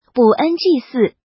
ǹg ńg
ng4.mp3